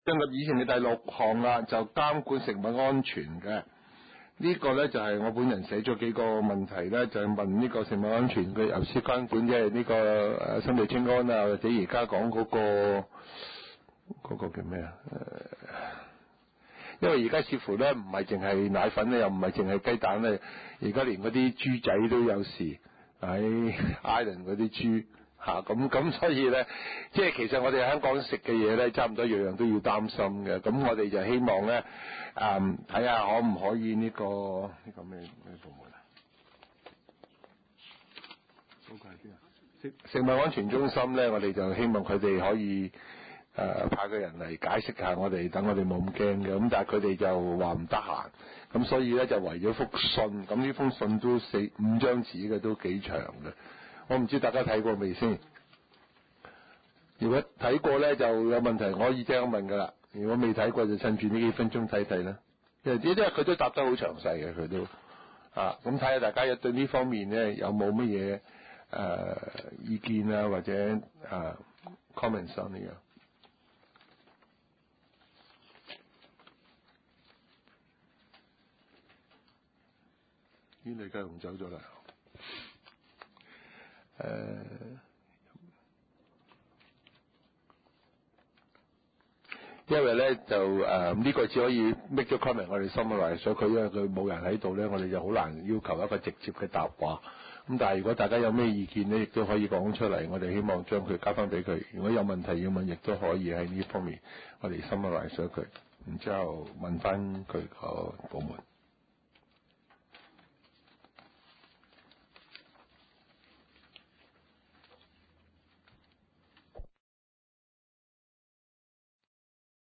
食物及環境衛生委員會第七次會議
灣仔民政事務處區議會會議室